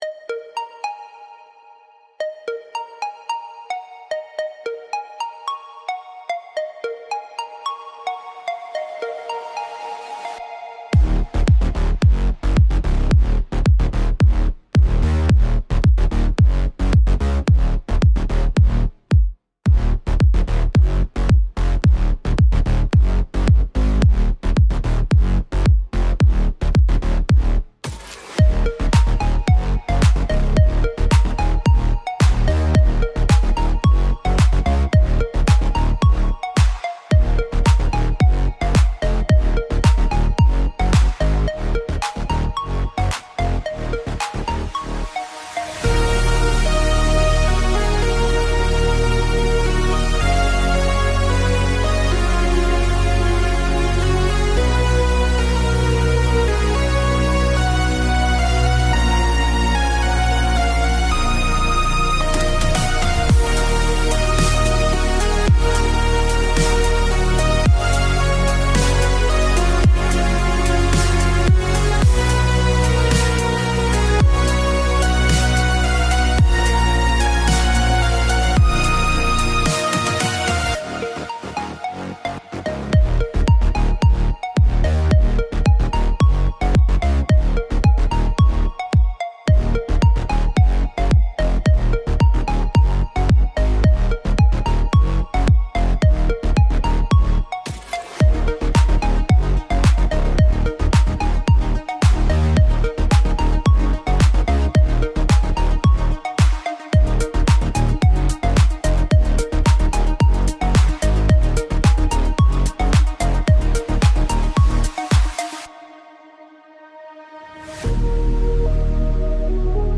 ハイテクなデジタルテクスチャ、洗練されたネオンの雰囲気、クリーンなエレクトロニックビート、プロフェッショナルで先進的な、現代的で未来的なシンセウェーブ、110bpm、インストゥルメンタル
ネオンのグリッドや宇宙のような広がりを感じる、疾走感のあるシンセウェーブ。